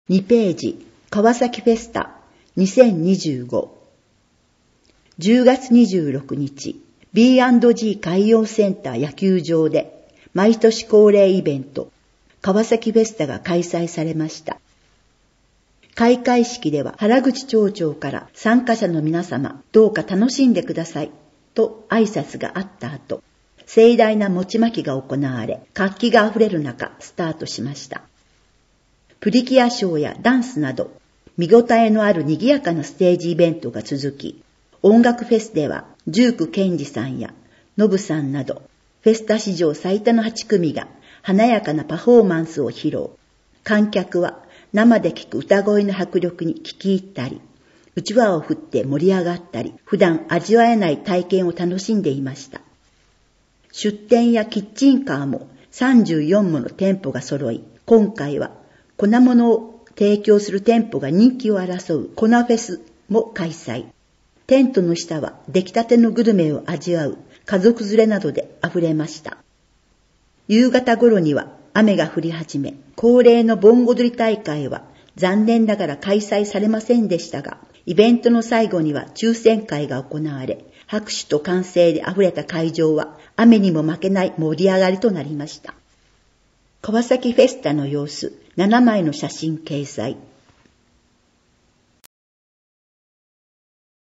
『広報かわさき』を音訳している川崎町朗読ボランティア「ひまわり」の作成した音声データを掲載しています。